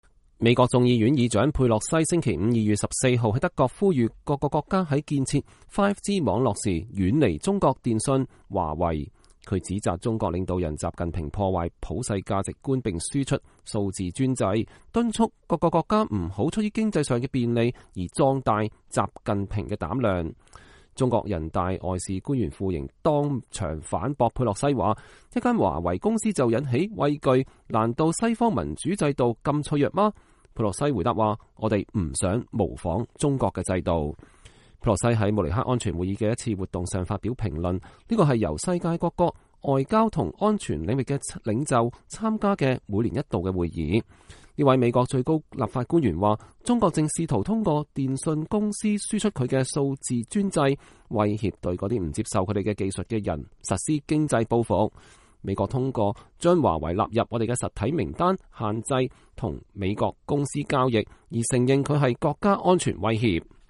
眾議院議長佩洛西在慕尼黑安全會議的首日活動中講話。(2020年2月14日)
佩洛西在慕尼黑安全會議的一次活動上發表了評論。
傅瑩的挑戰式提問引來了觀眾席中的掌聲。